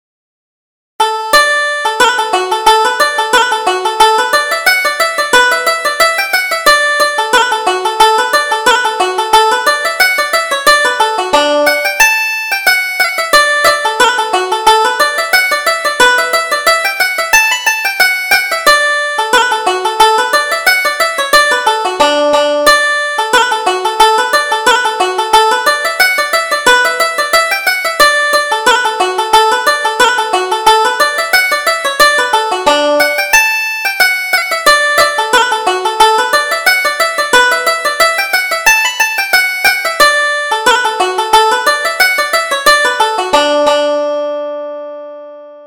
Reel: The Merry Blacksmith